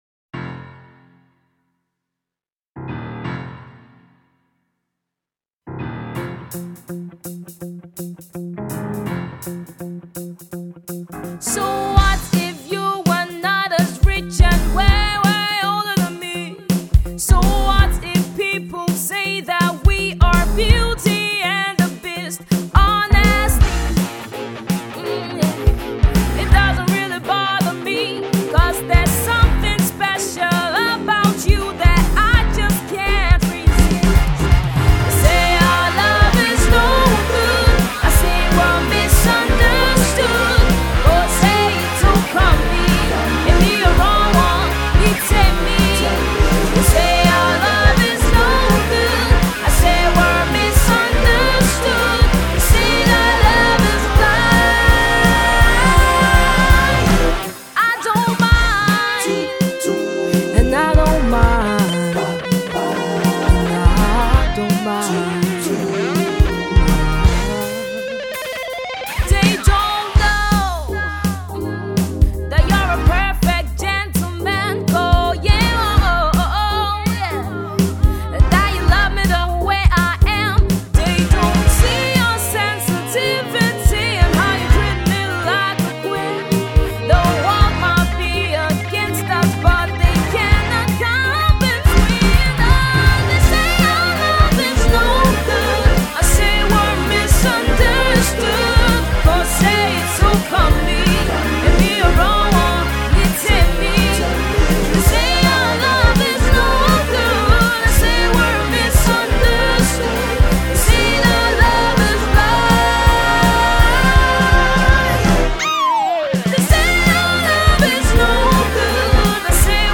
My style of music is Afro soul, blues, jazz, folk and swing.